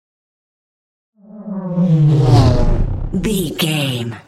Scifi pass by vehicle
Sound Effects
futuristic
high tech
pass by